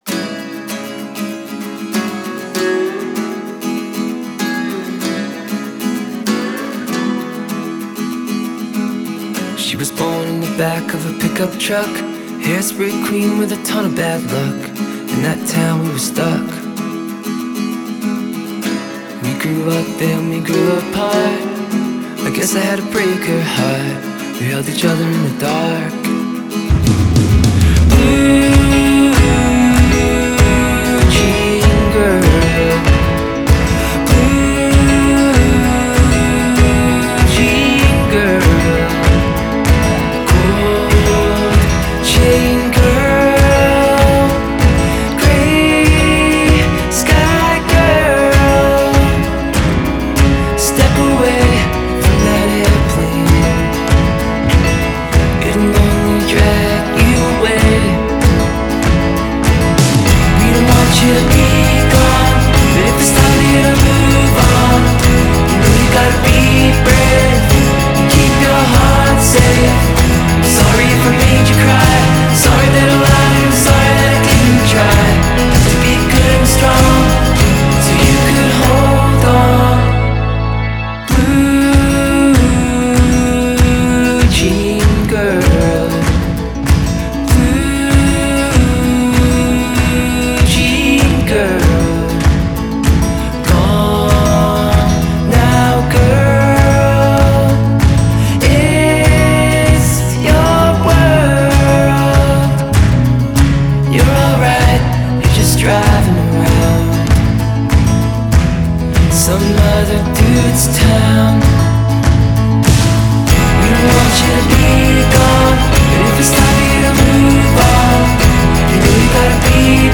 Genre: Indie Pop, Indie Folk